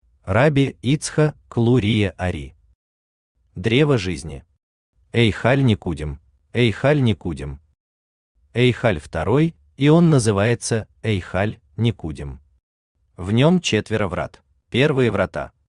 Эйхаль Никудим Автор Рабби Ицха́к Лу́рия бен Шломо Ашкена́зи Ари Читает аудиокнигу Авточтец ЛитРес.